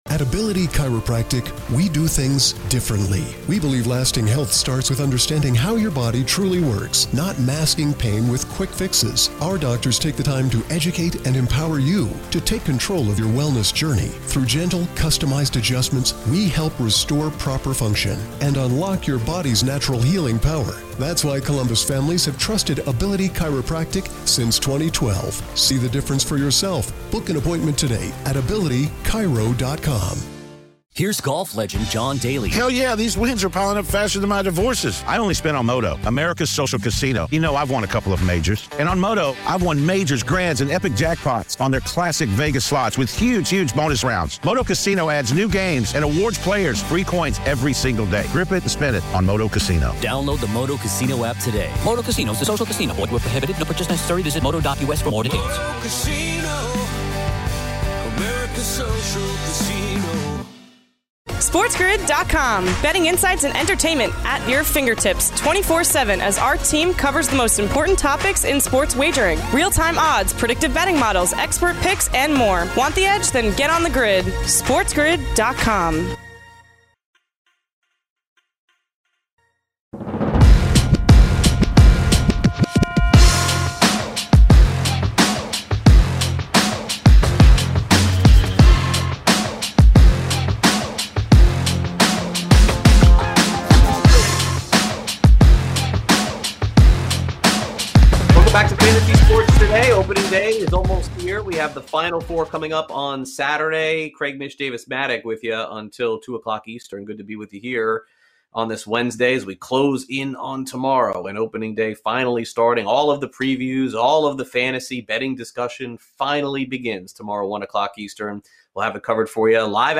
3/31 Hour 2: MLB GM Interviews, MLB Futures, MLB Win Totals/Playoff Odds, & More